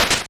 snd_switchpull_n.wav